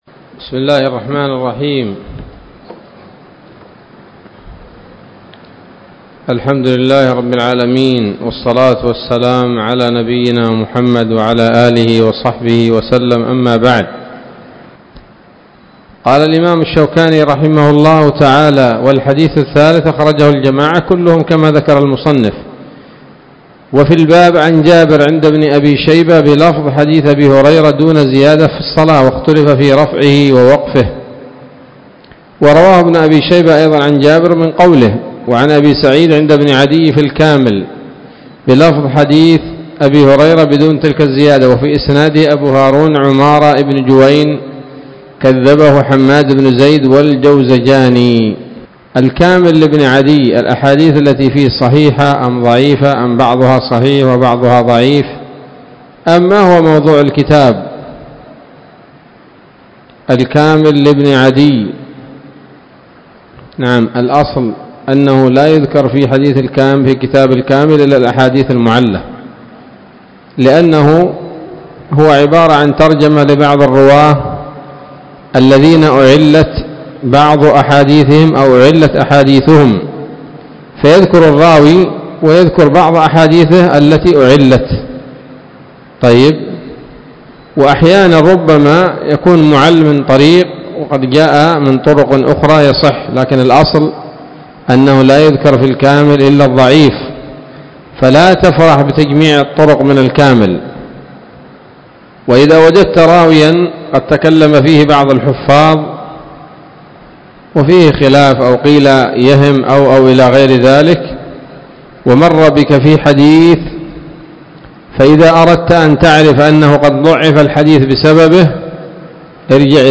الدرس التاسع من أبواب ما يبطل الصلاة وما يكره ويباح فيها من نيل الأوطار